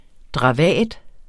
Udtale [ dʁɑˈvæˀd ]